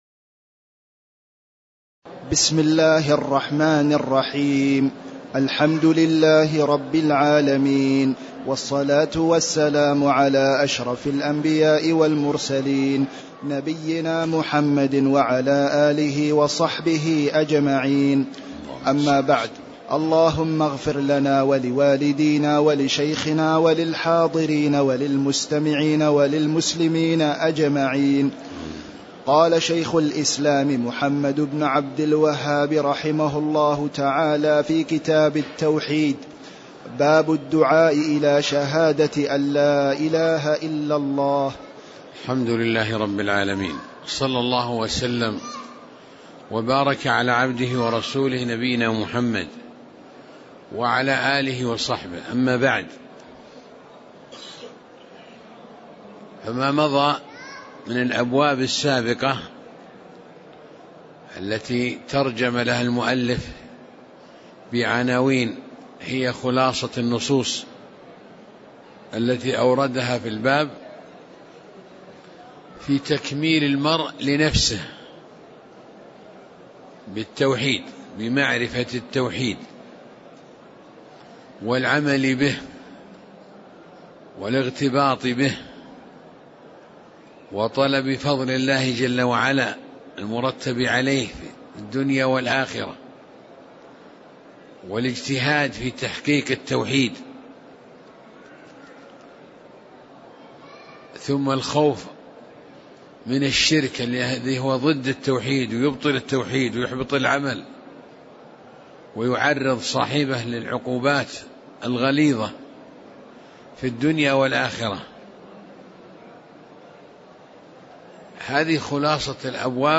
تاريخ النشر ٥ رجب ١٤٣٨ هـ المكان: المسجد النبوي الشيخ